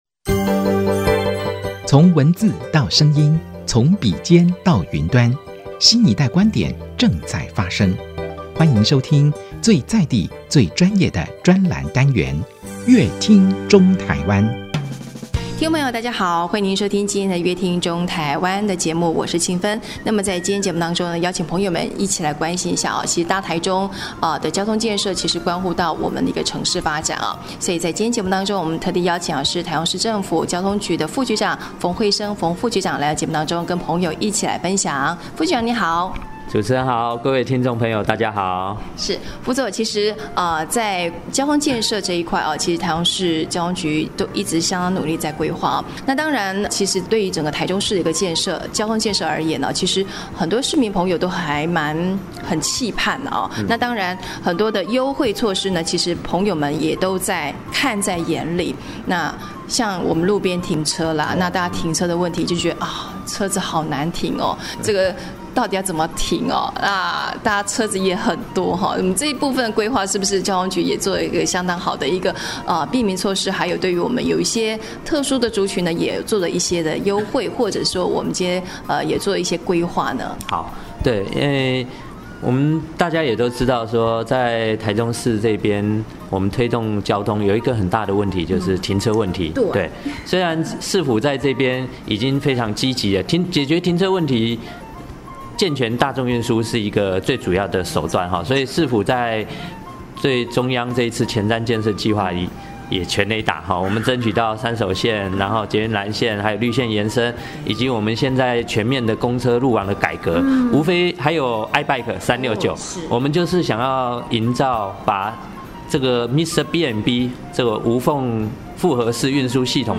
本集來賓：臺中市政府交通局馮輝昇副局長 本集主題：「打造友善停車小確幸-台中市8/1起推停車優惠」 本集內容：